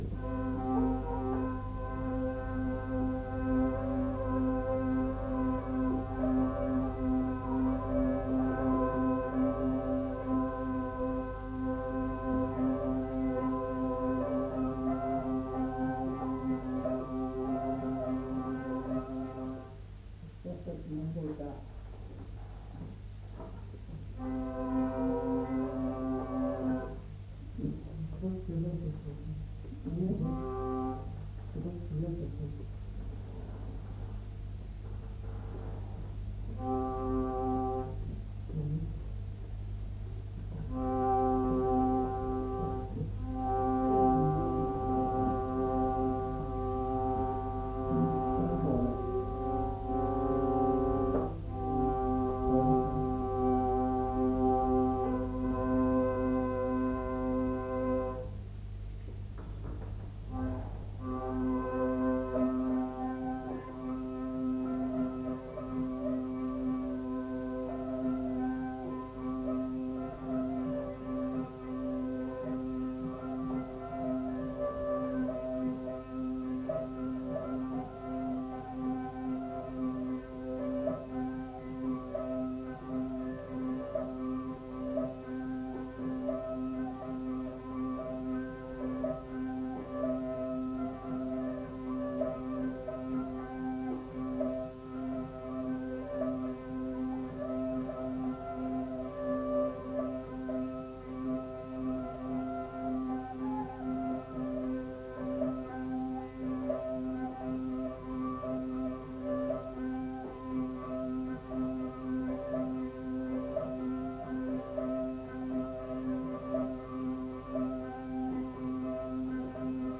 Aire culturelle : Petites-Landes
Genre : morceau instrumental
Instrument de musique : vielle à roue
Danse : polka